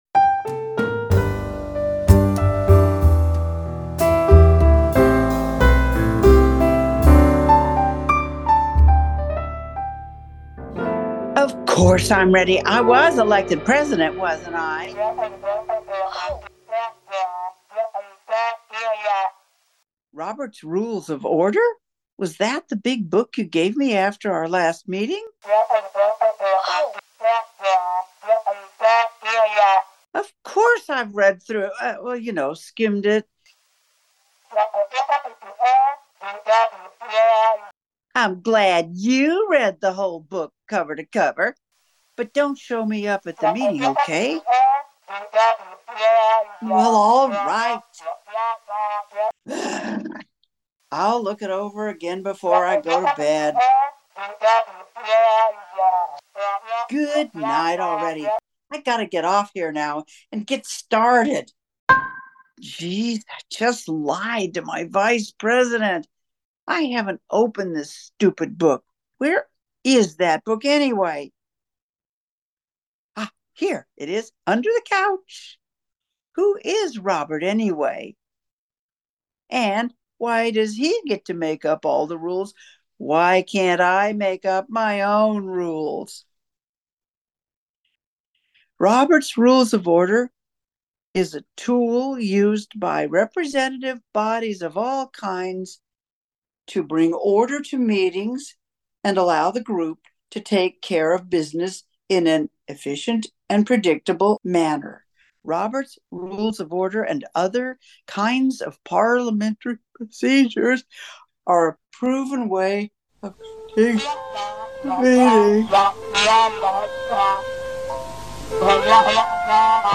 Robert's Rules of Order Explained, skit from the 2023 Leadership Seminar